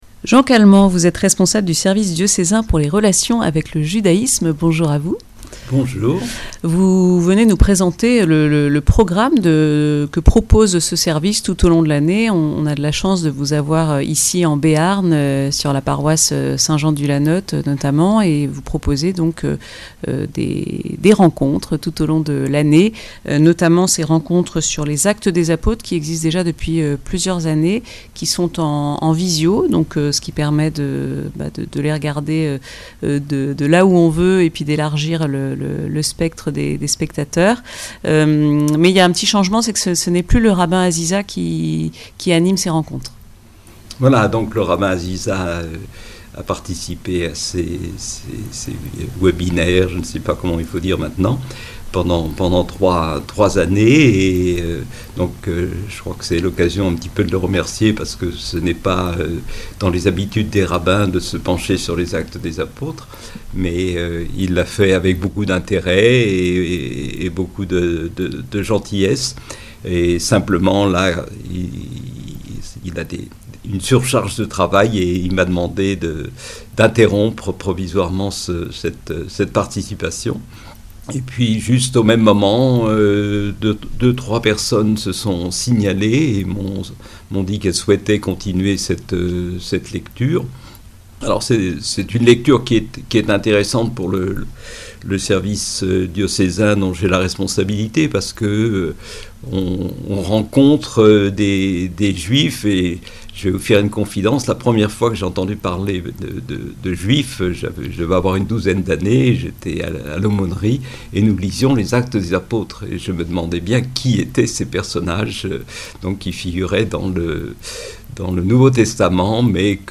Une interview